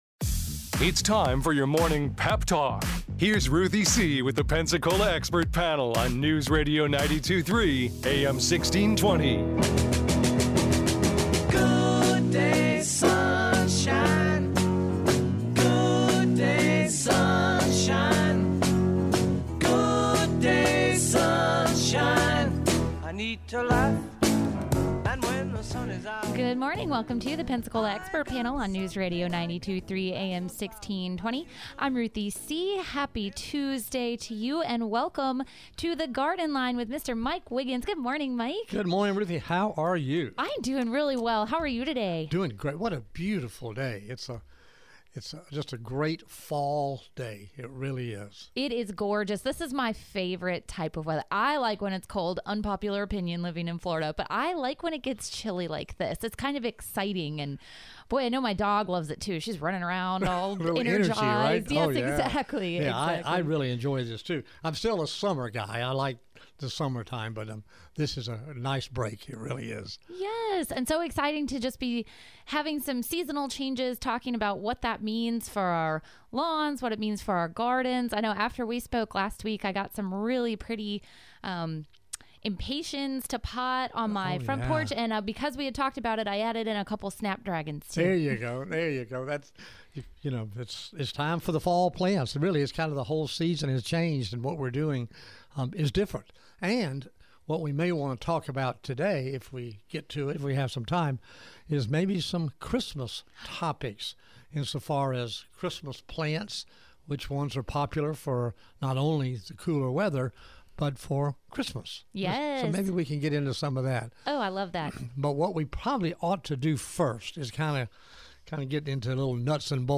Listeners call and text in their questions including best mole removal methods (Have you ever tried Juicy Fruit gum?), how to get rid of tree roots and stumps, and how to address scale infection in a palm.